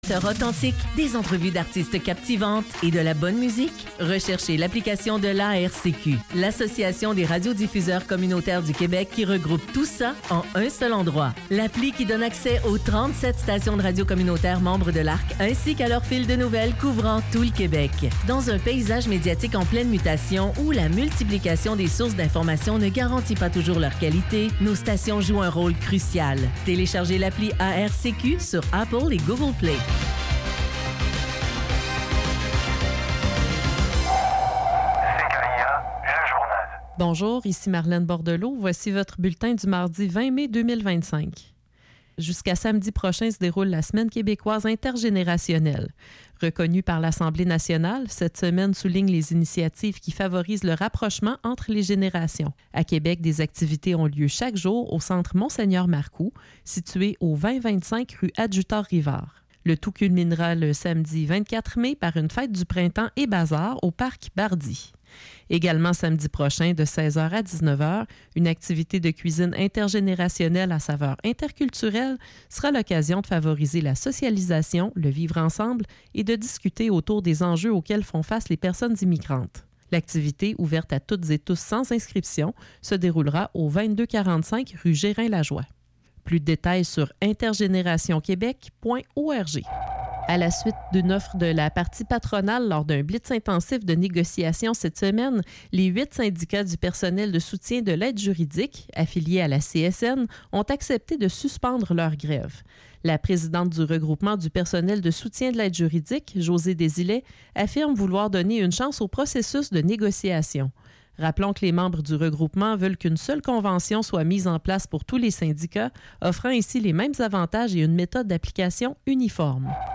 CKIA 88,3 FM - Radio urbaine